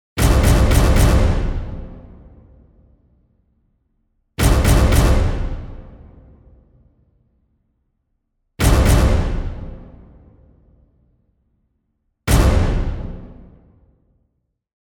mf_SE-8757-accent_hits.mp3